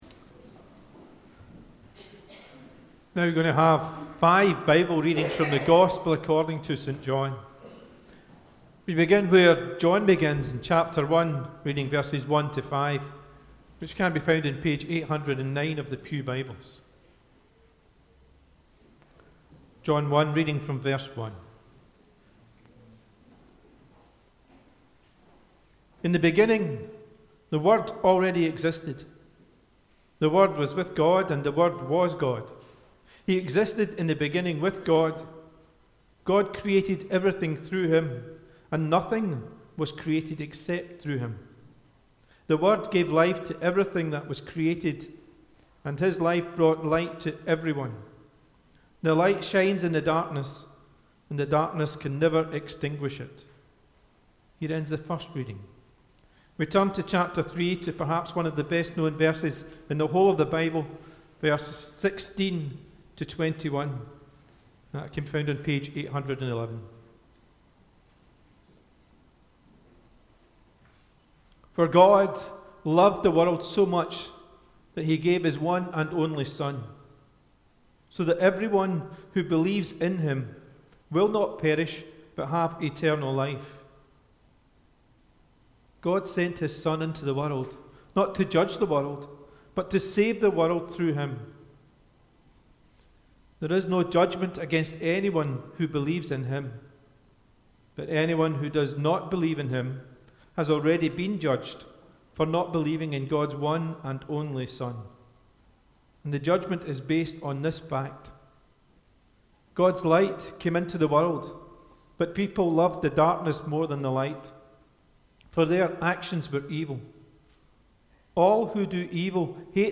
The Gospel According to John - Uddingston Burnhead Parish Church